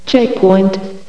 checkpoint.ogg